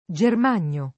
Germagno [ J erm # n’n’o ]